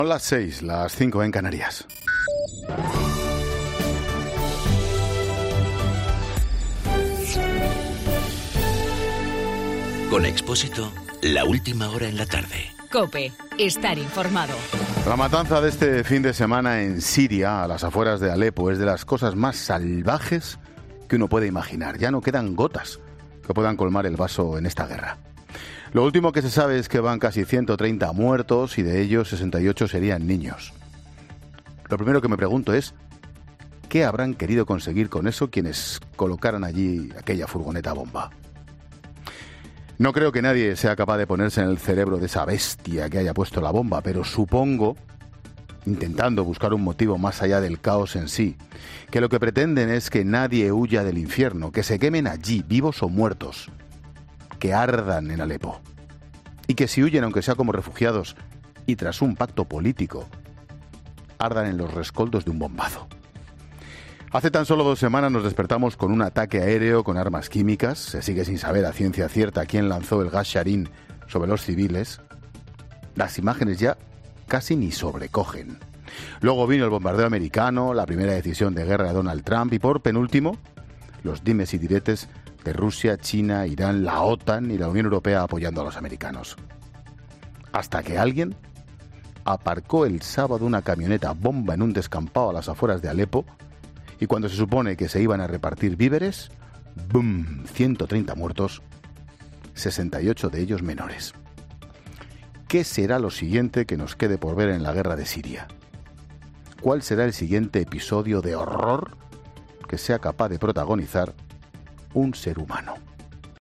AUDIO: Monólogo 18h.